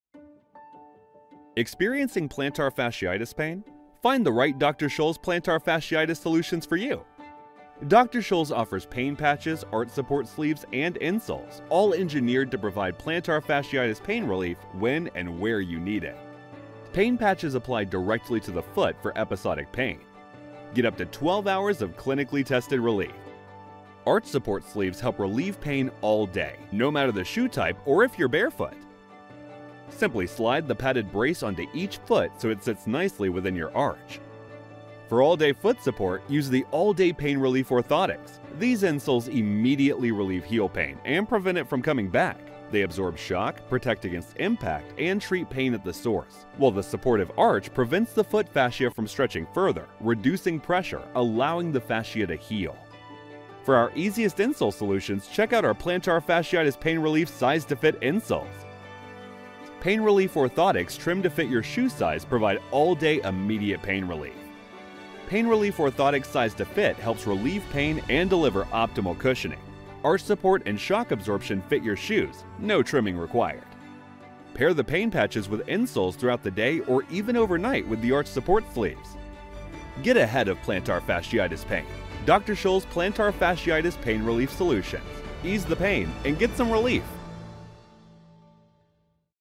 Anglais (Américain)
Distinctive, Polyvalente, Chaude, Amicale, Corporative
Corporate